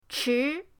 chi2.mp3